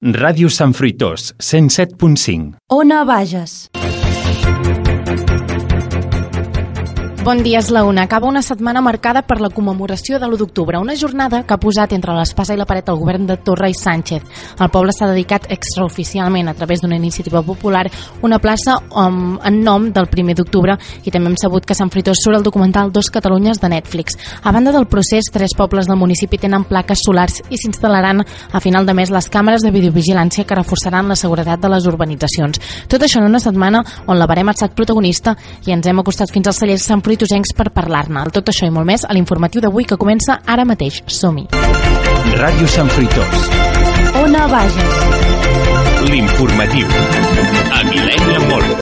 Indicatiu de l'emissora i inici de l'informatiu amb el sumari: commemoració de l'1 d'octubre, la verema.
Informatiu